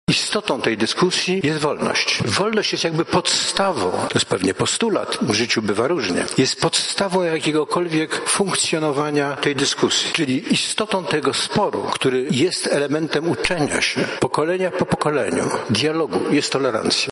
Obecność Boga to forma dialogu– mówi profesor Uniwersytetu Warszawskiego, dyrektor Żydowskiego Instytutu Historycznego Paweł Śpiewak: